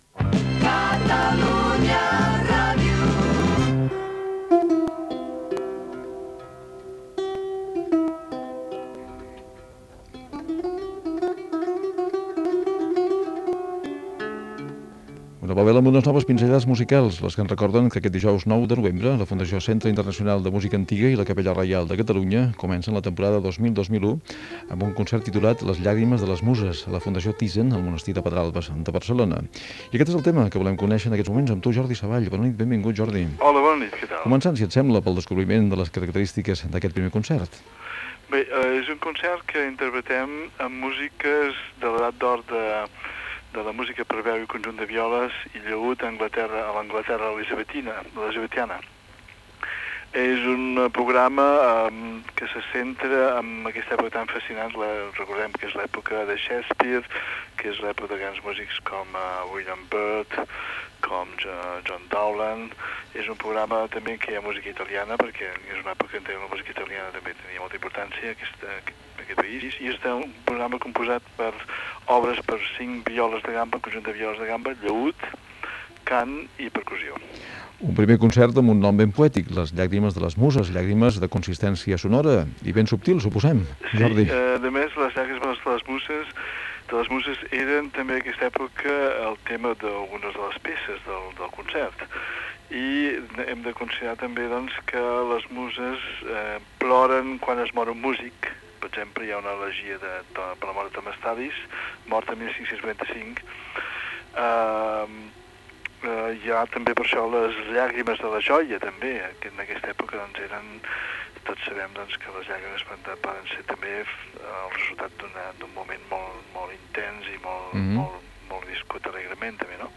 Indicatiu de Catalunya Ràdio, entrevista al violagambista i director de orquestra Jordi Savall en relació al concert "Les llàgrimes i les muses" de la Fundació Internacional de música antiga i la Capella Reial de Catalunya a la Fundació Thyssen al Monestir de Pedralbes de Barcelona. Indicatiu de Catalunya Ràdio.